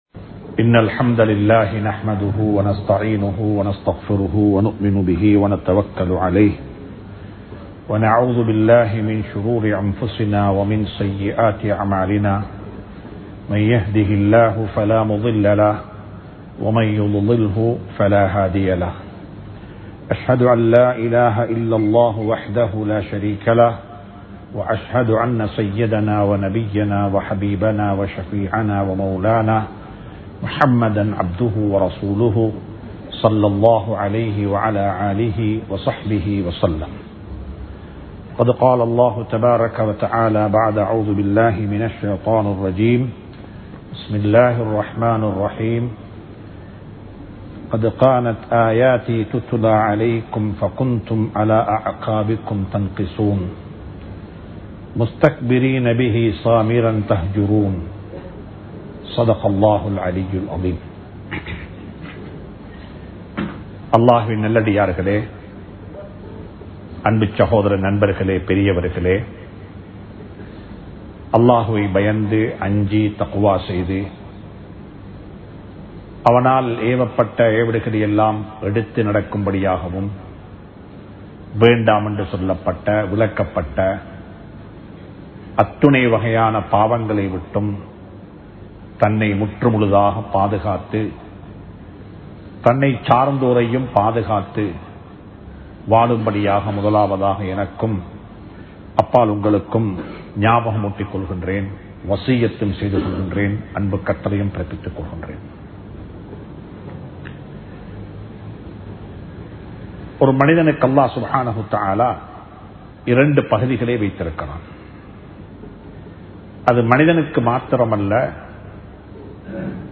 உள்ளமும் முகமும் | Audio Bayans | All Ceylon Muslim Youth Community | Addalaichenai
Dehiwela, Waidya Road, Al Azhar Jumua Masjith